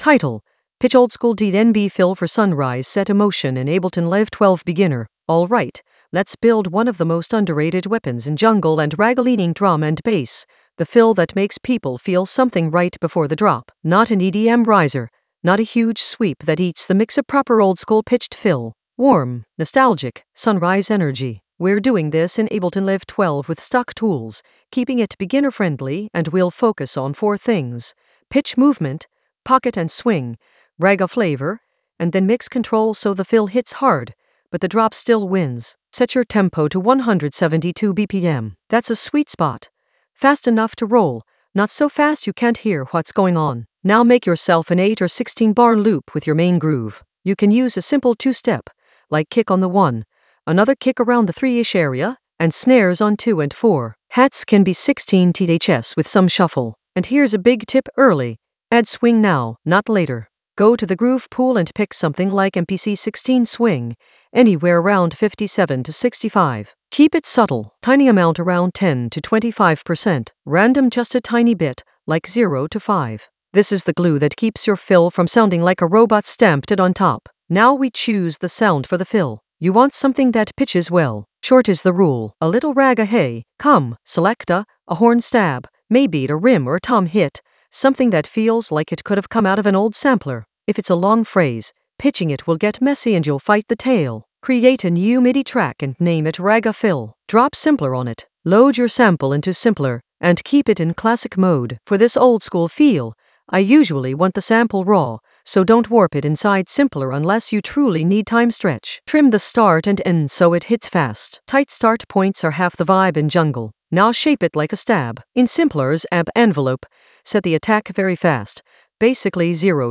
Narrated lesson audio
The voice track includes the tutorial plus extra teacher commentary.
An AI-generated beginner Ableton lesson focused on Pitch oldskool DnB fill for sunrise set emotion in Ableton Live 12 in the Ragga Elements area of drum and bass production.